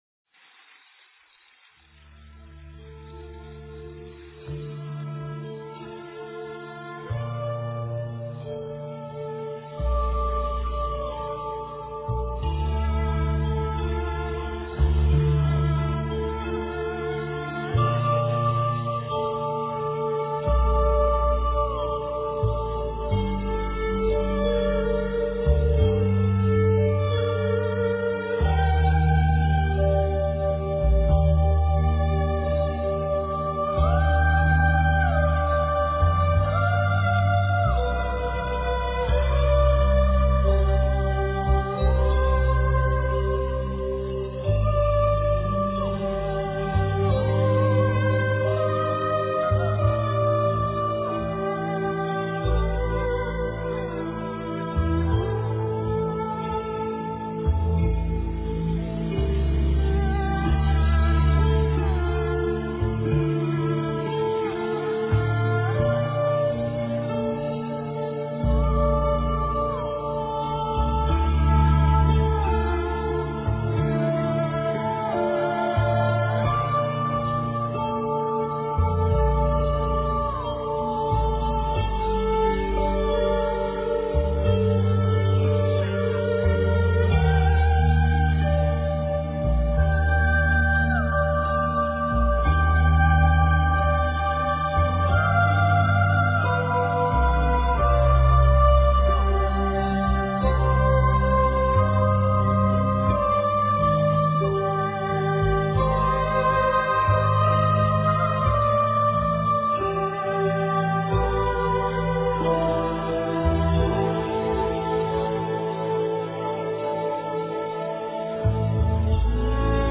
善 Kindness--禅定音乐 冥想 善 Kindness--禅定音乐 点我： 标签: 佛音 冥想 佛教音乐 返回列表 上一篇： 和谐 Harmony--禅定音乐 下一篇： 因果 Reason--禅定音乐 相关文章 摩诃般若菠萝蜜--佚名 摩诃般若菠萝蜜--佚名...